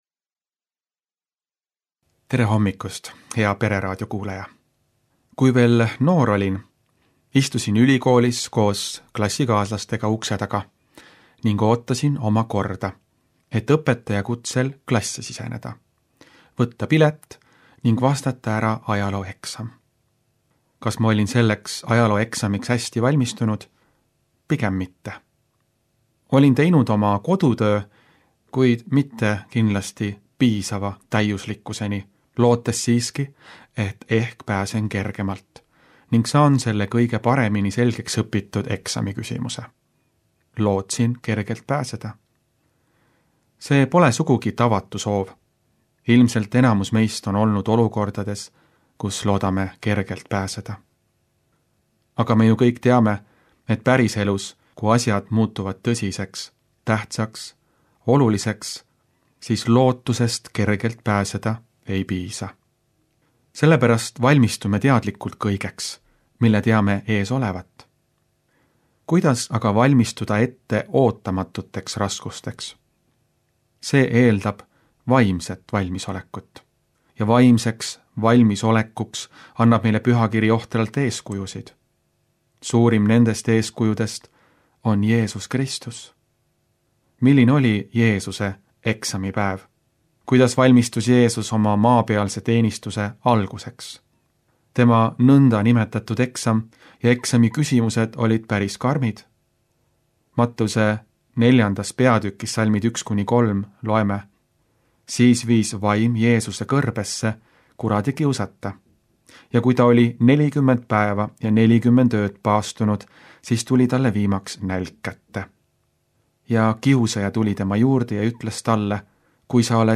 Hommikupalvused